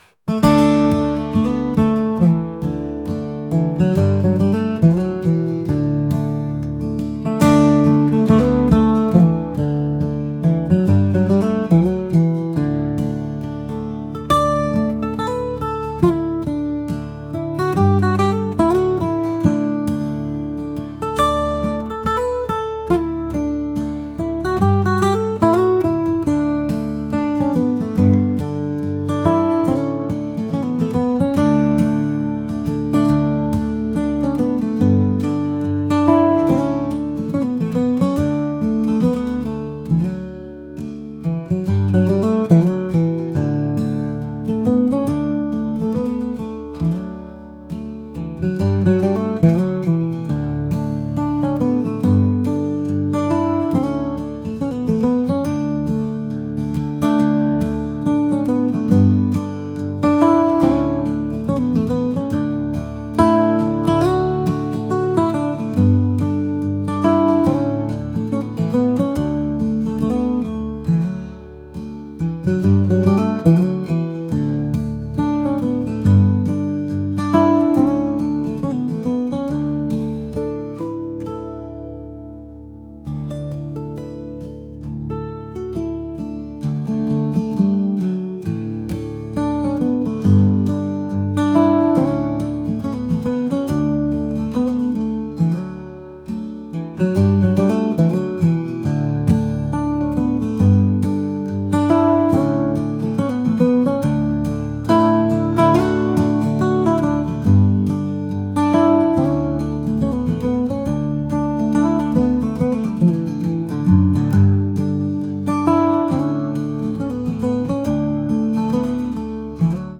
folk | acoustic | indie